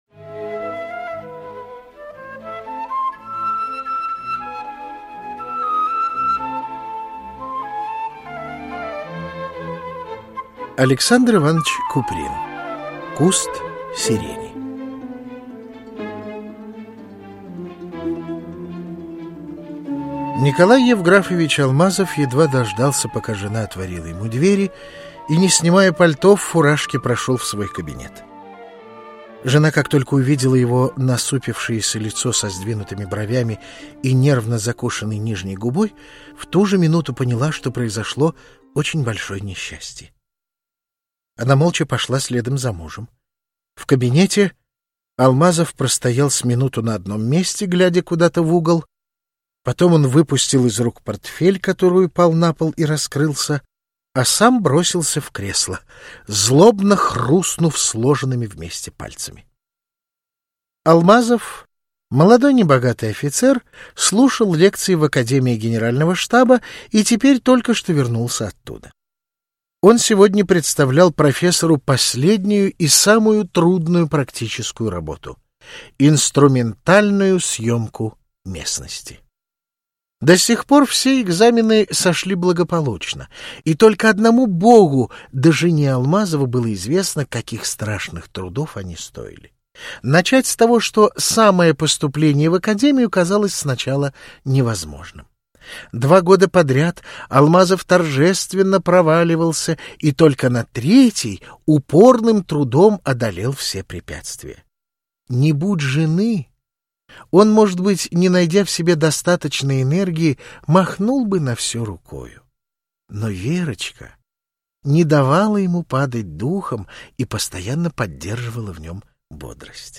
Здесь вы можете слушать онлайн аудиокнигу Александра Куприна с рассказом "Куст сирени" в исполнении Алексея Колгана.